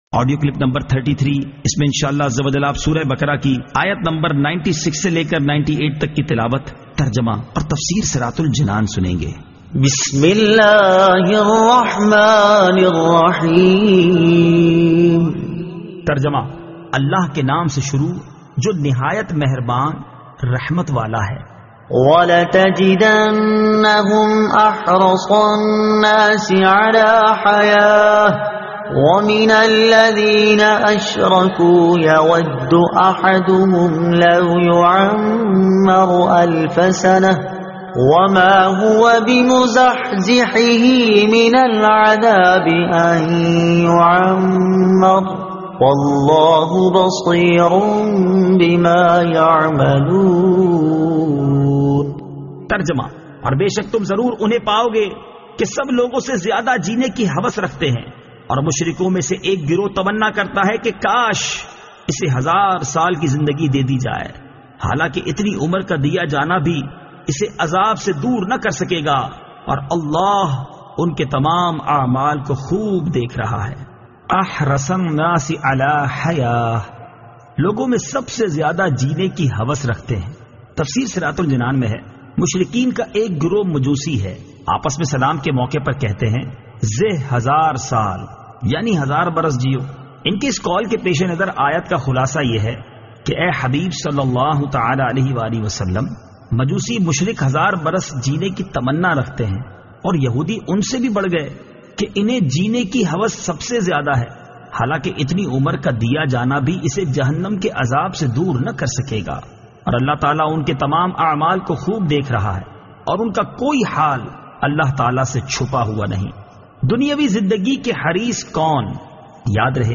Surah Al-Baqara Ayat 96 To 98 Tilawat , Tarjuma , Tafseer
2019 MP3 MP4 MP4 Share سُوَّرۃُ البَقَرَۃ آیت 96 تا 98 تلاوت ، ترجمہ ، تفسیر ۔